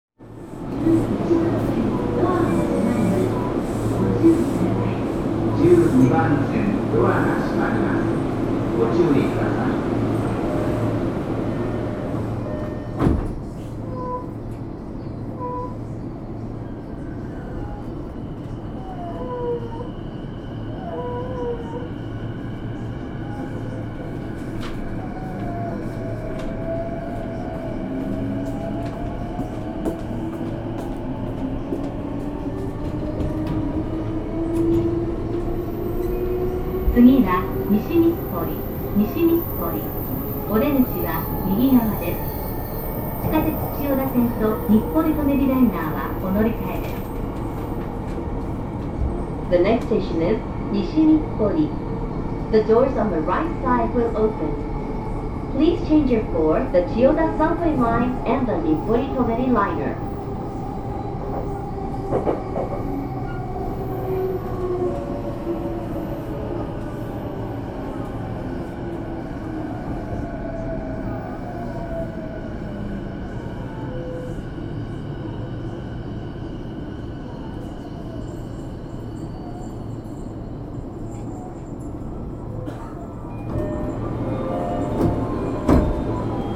走行音
録音区間：日暮里～西日暮里(お持ち帰り)